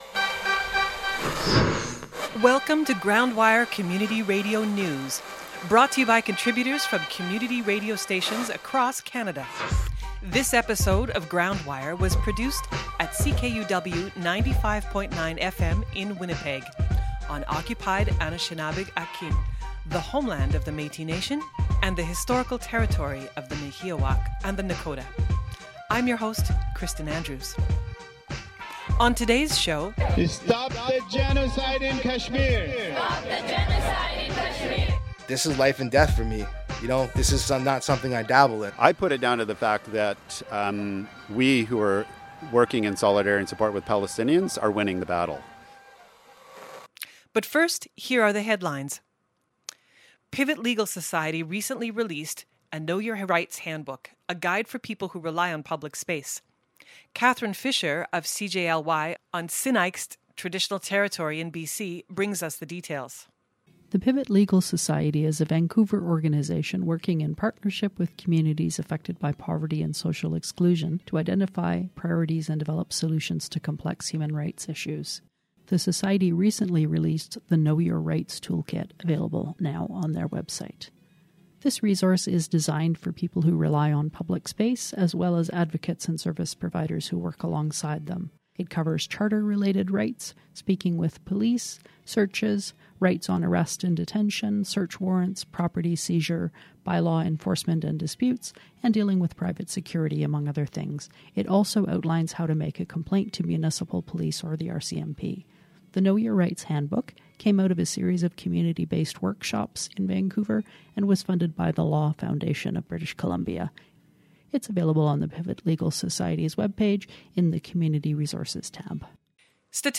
Community radio news from coast to coast to coast